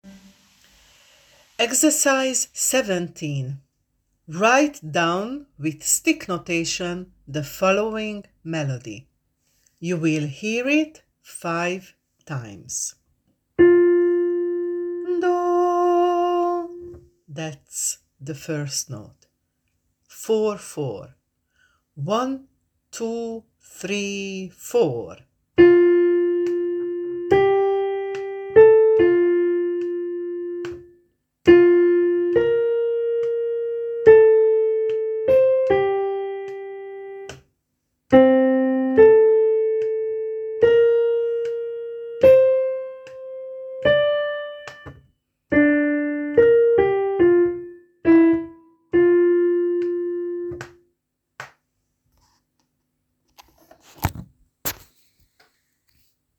17.Write down with stick notation the following melody. You will hear it 5 times: